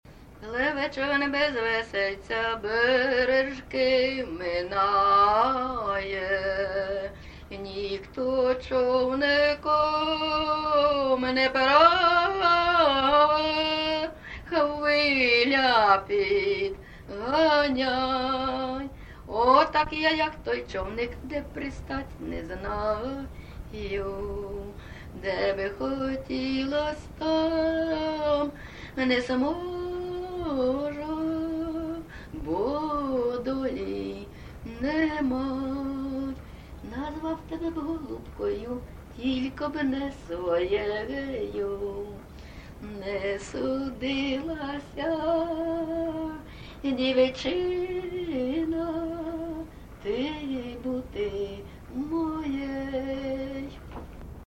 ЖанрПісні з особистого та родинного життя
Місце записум. Маріуполь, Донецька обл., Україна, Північне Причорноморʼя